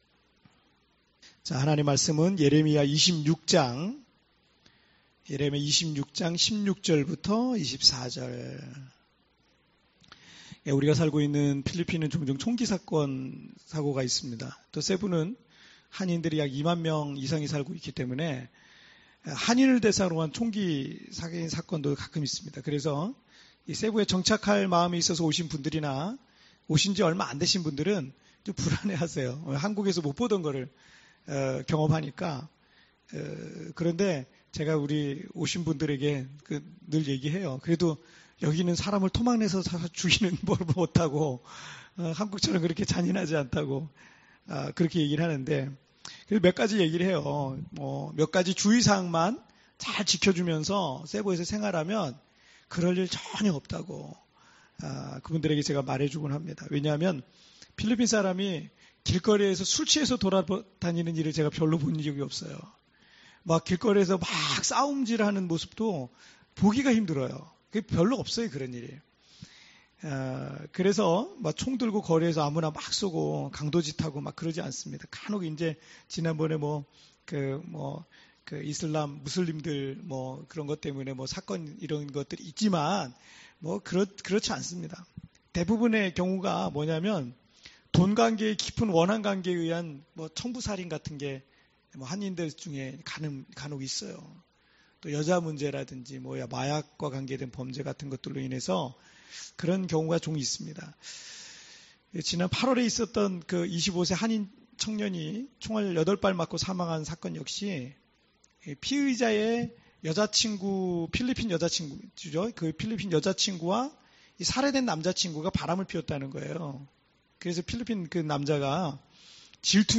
오늘의 묵상
10.18.새벽기도.mp3